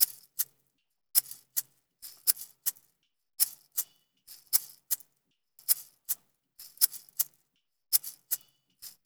EPH SHAKER.wav